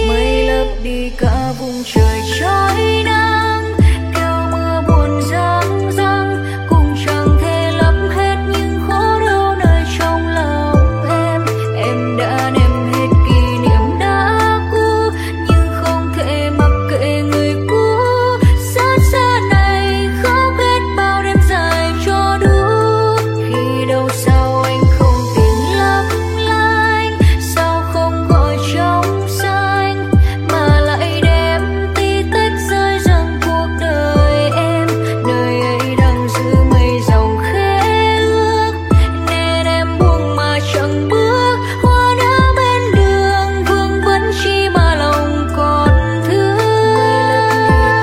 Nhạc chuông 7 lượt xem 11/03/2026